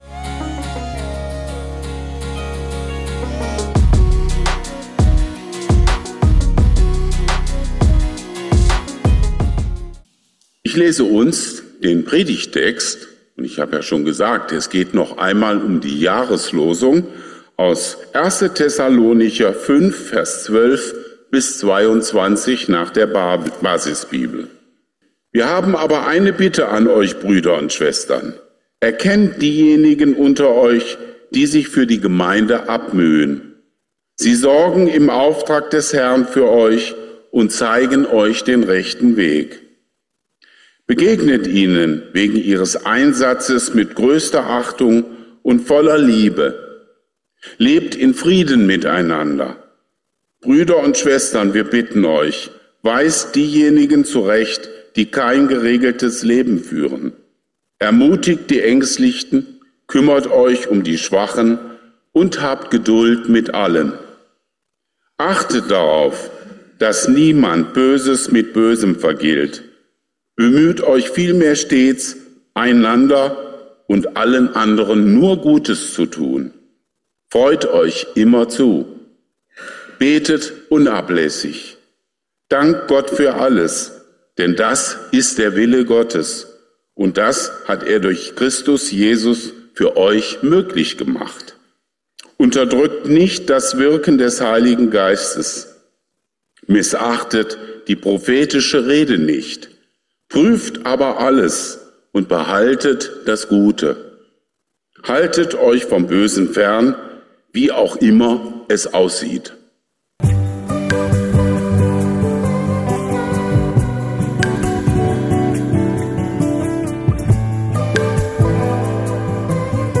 Daraus ergeben sich für das kommende Jahr neue Aufgaben. Viel Segen beim Hören der Predigt.